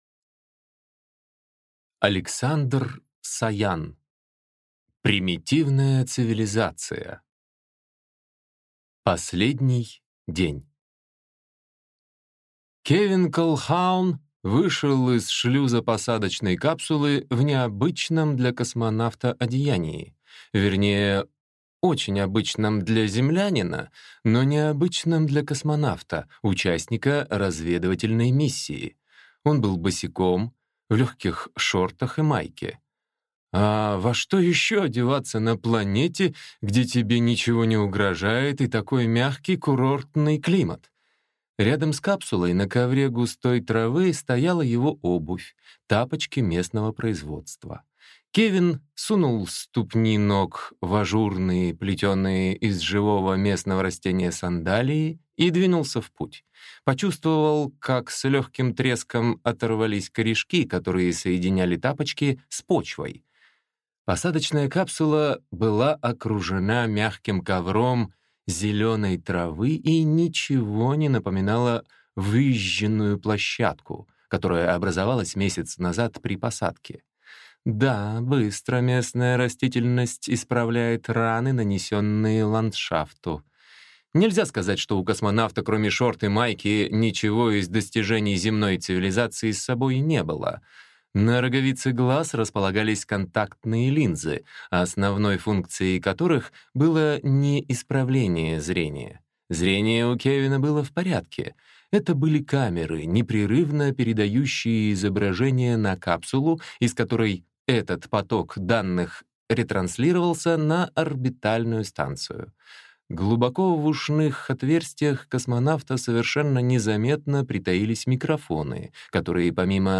Аудиокнига Примитивная цивилизация | Библиотека аудиокниг